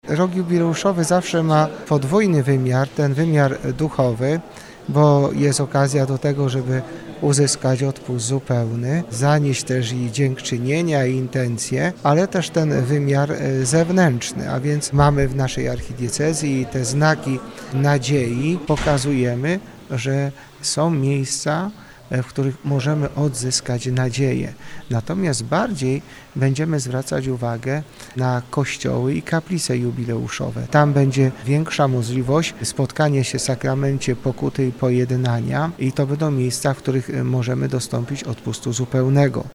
Mówi o. bp Jacek Kiciński CMF, biskup pomocniczy Archidiecezji Wrocławskiej.